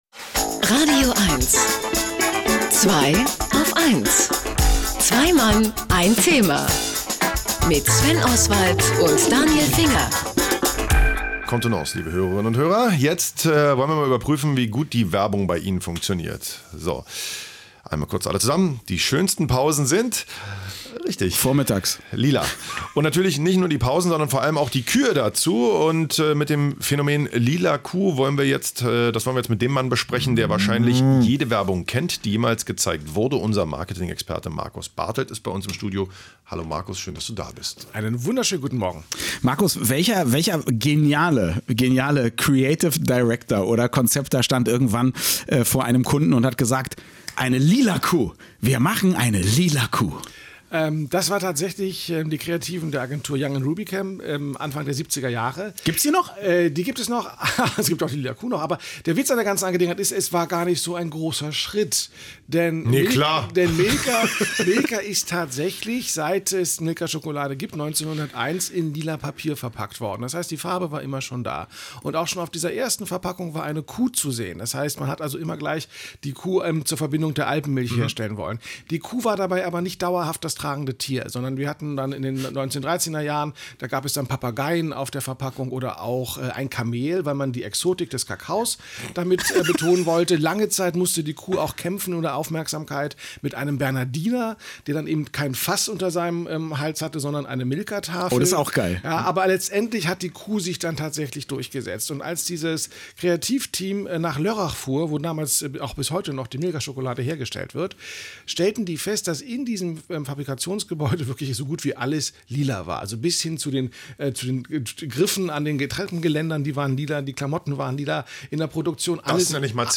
Ich und du, Müllers Kuh, Müllers Esel das sind die Herren von „Zwei auf eins„… nein, natürlich nicht, aber das Thema der heutigen Sendung lautet „Kuh“ und ich bin in das radioeins-Studio geladen, um über die berühmteste lila Kuh ever zu sprechen: die Milka-Kuh.